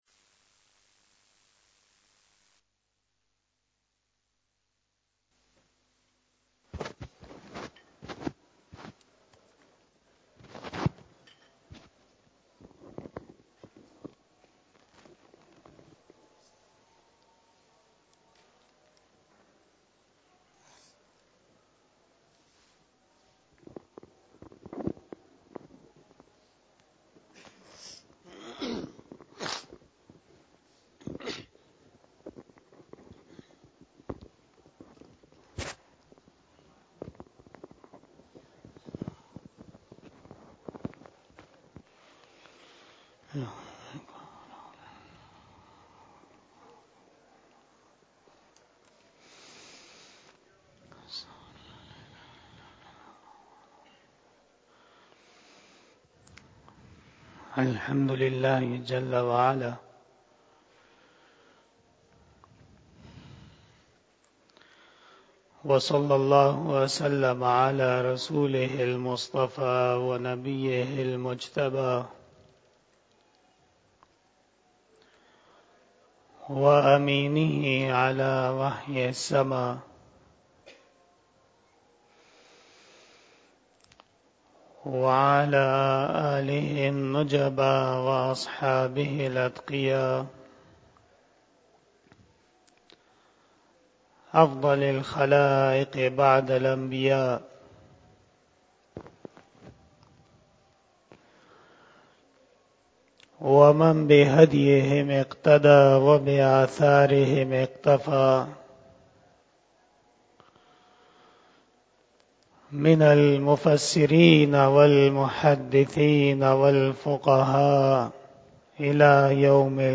04 BAYAN E JUMMA 27 Janaury 2023 (03 Rajab 1444H)